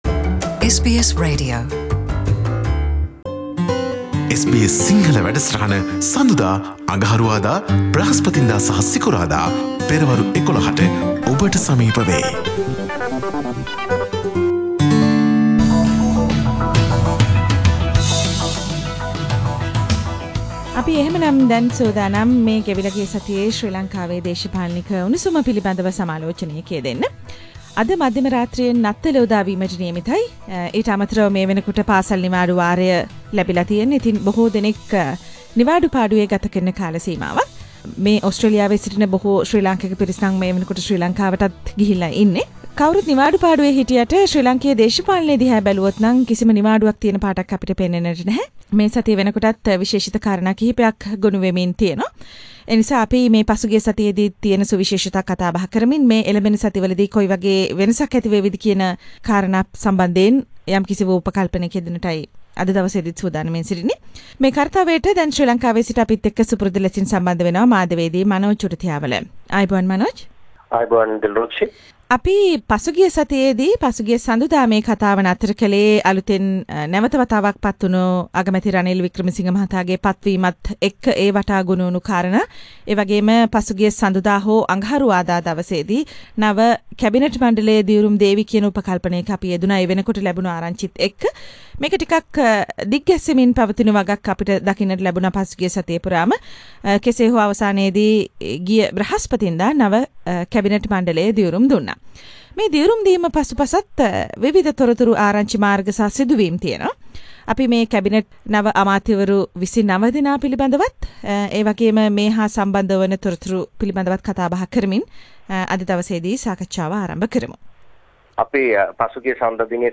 සතියේ දේශපාලන පුවත් සමාලෝචනය